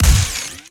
GUNTech_Sci Fi Shotgun Fire_04.wav